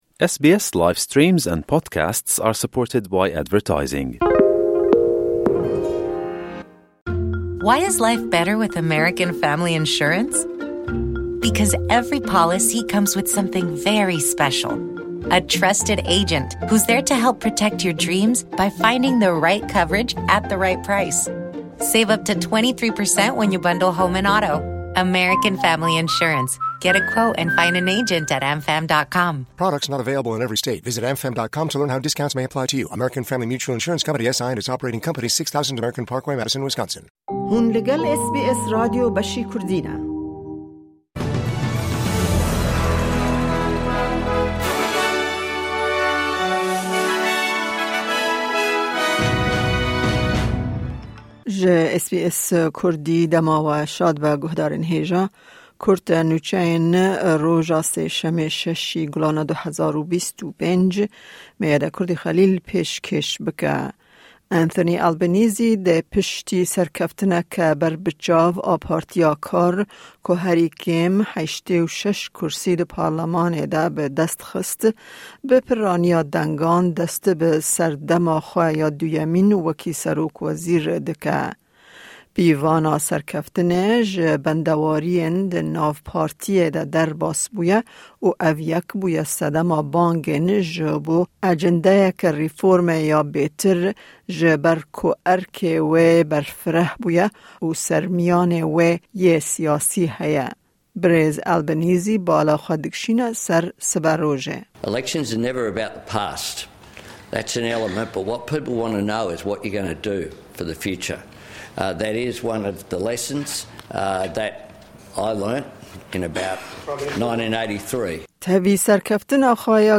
Kurte Nûçeyên roja Sêşemê 6î Gulana 2025